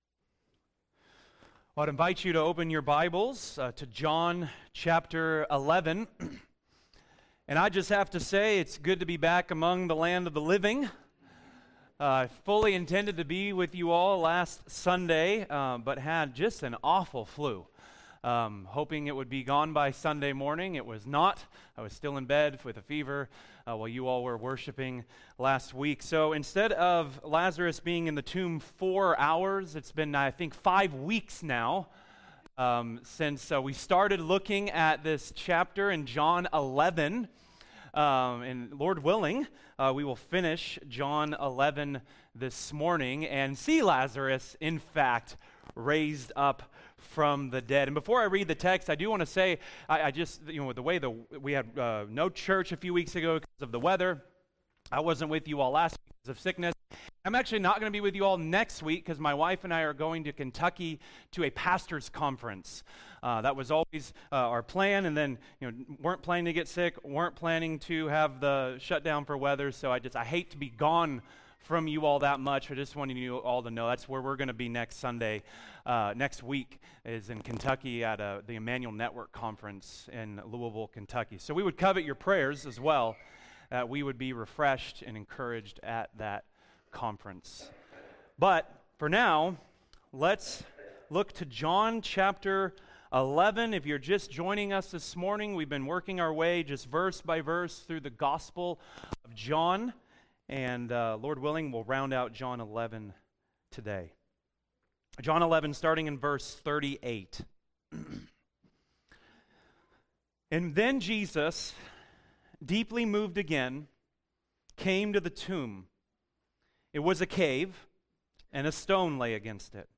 Sermons | Vine Community Church